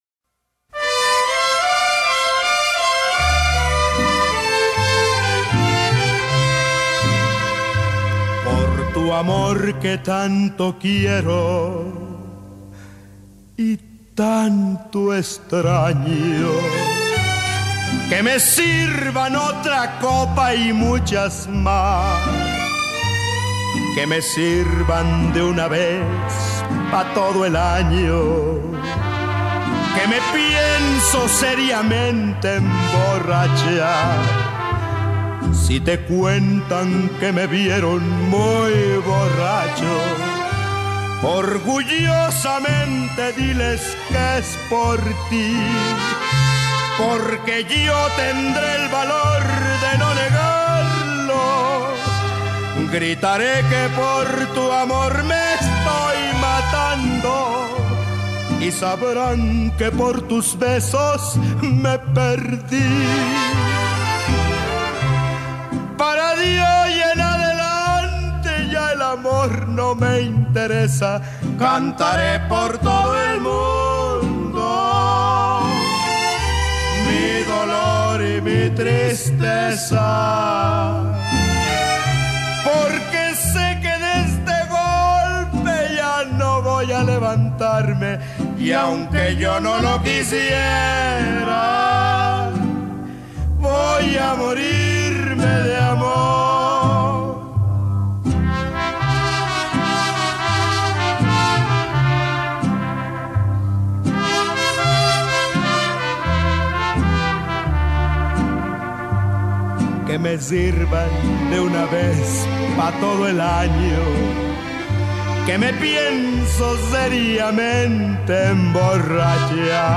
Mariachi